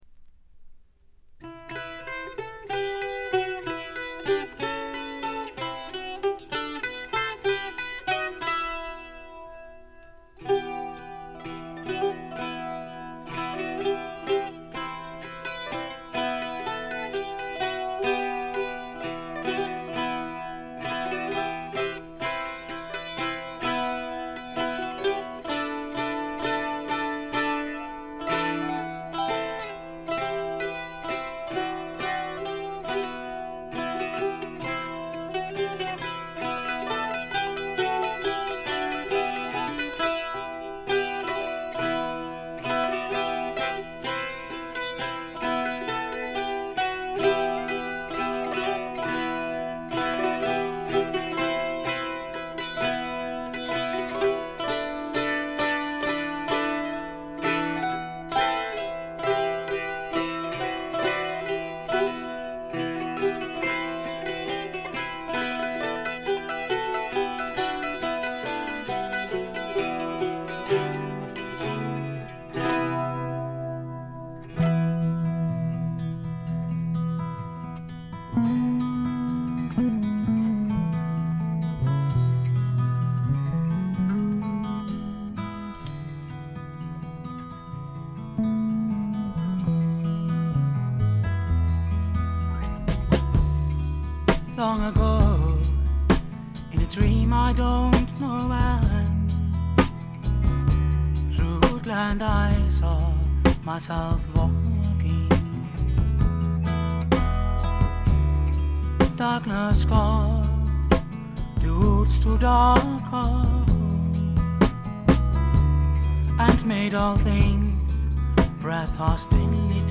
軽いチリプチが少々。
全体を貫く哀愁味、きらきらとしたアコースティックな美しさは文句なしに至上のもの。
試聴曲は現品からの取り込み音源です。